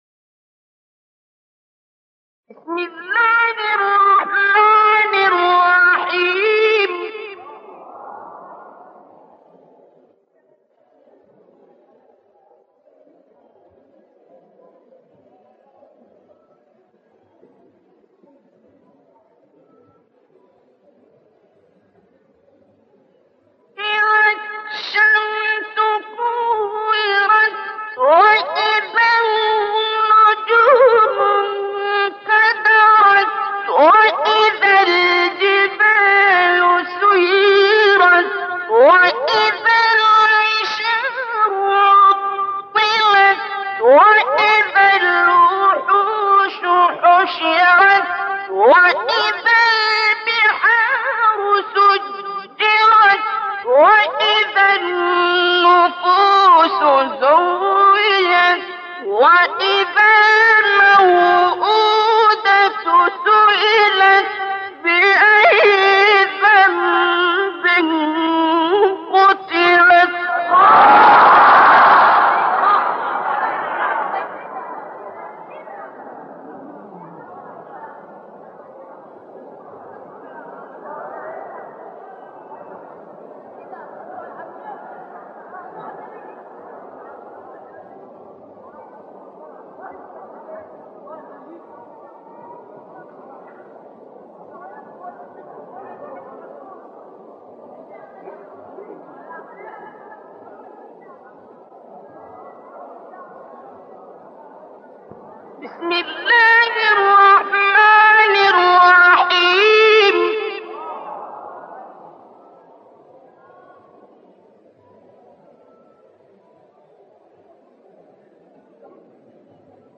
القارئ عبد الباسط محمد عبد الصمد - قصار السور.